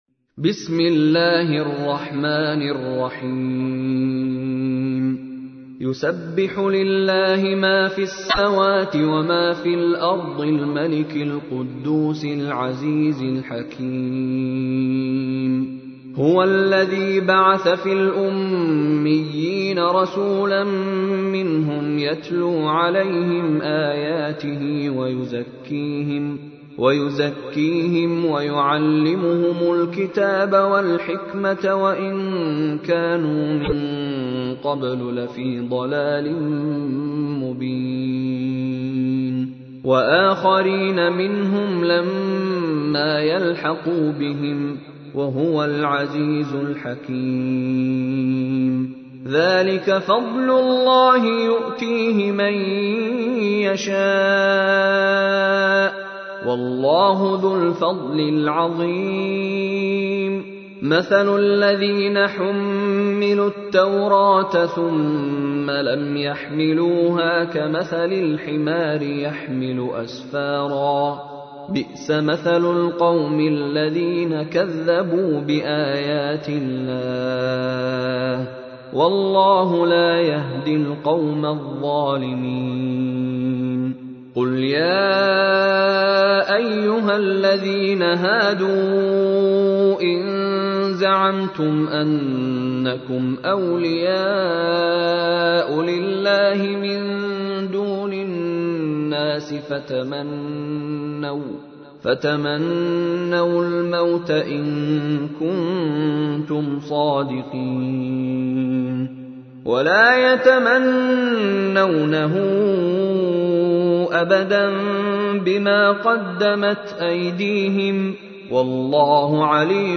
تحميل : 62. سورة الجمعة / القارئ مشاري راشد العفاسي / القرآن الكريم / موقع يا حسين